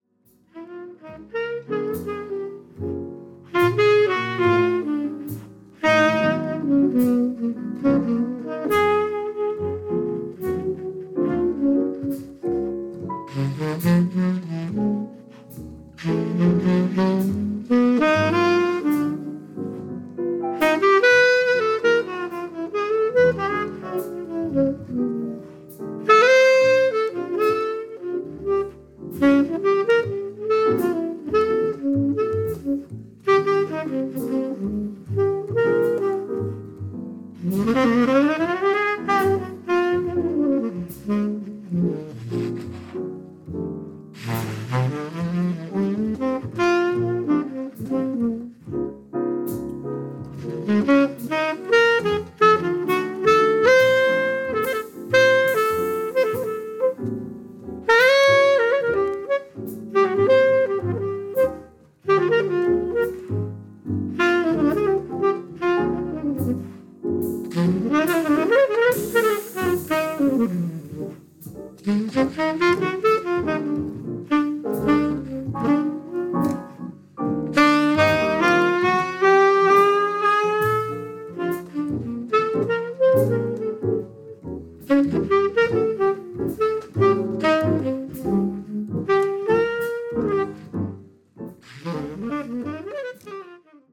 Bass
Piano
Tenor Saxophone